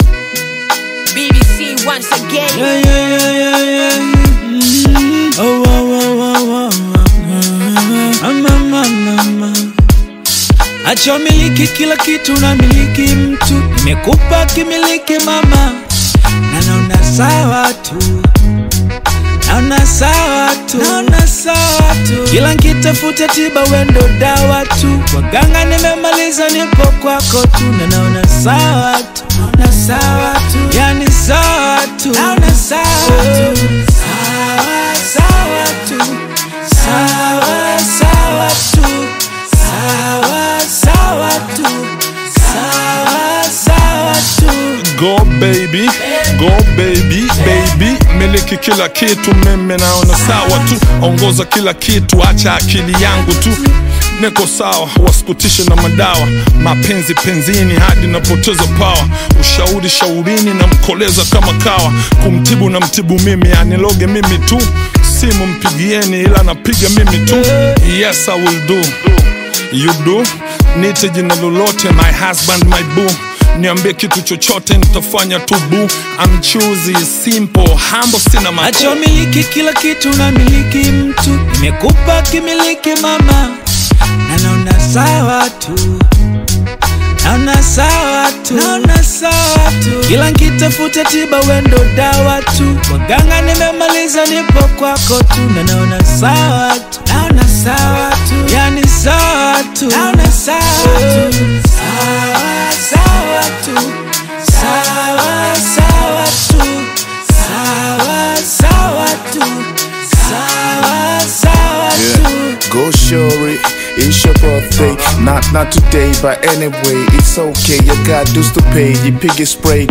Tanzanian Hip Hop
smooth, laid-back flow